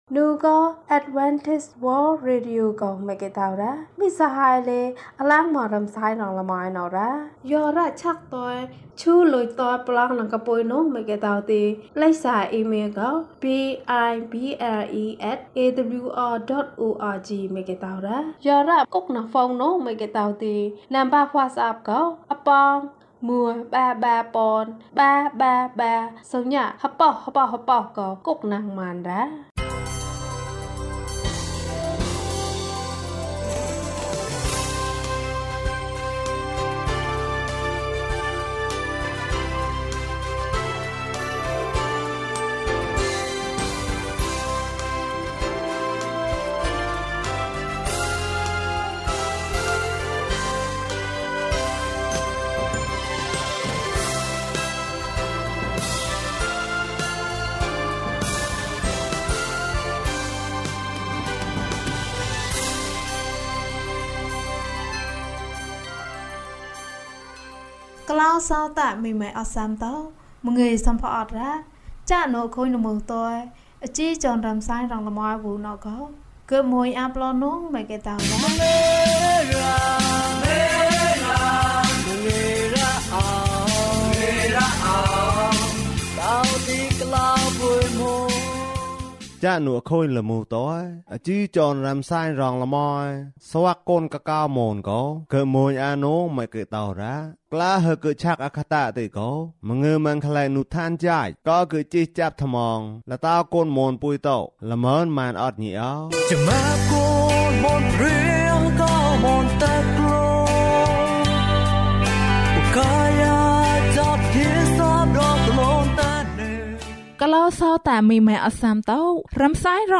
သင့်ဘဝအတွက် ဘုရားသခင် ကယ်တင်ခြင်းအတွက် အခြားသူများကို မျှဝေပါ။ ကျန်းမာခြင်းအကြောင်းအရာ။ ဓမ္မသီချင်း။ တရားဒေသနာ။